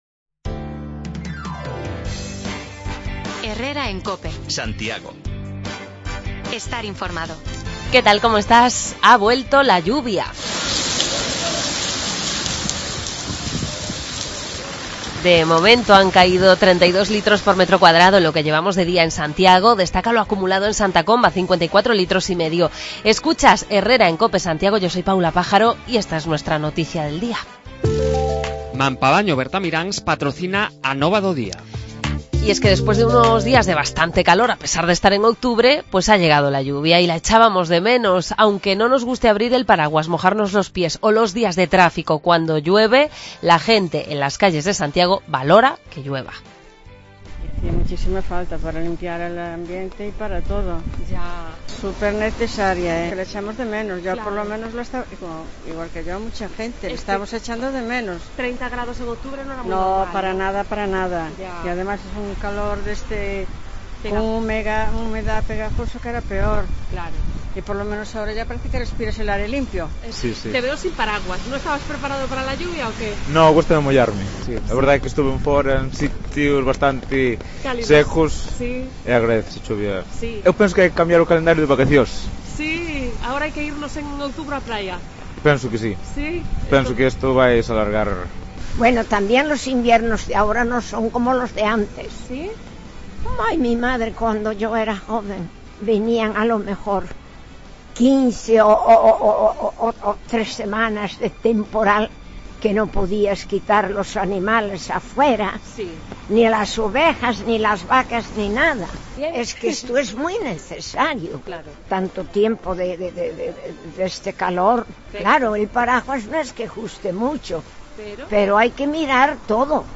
Nos hemos colado en una función de títeres, porque está en marcha la edición número 28 de Galicreques y este fin de semana hay un montón de espectáculos por todo Santiago. No todos los escenarios son así de agradables: en las oficinas de Correos hemos comprobado también las consecuencias de la huelga en el servicio de limpieza.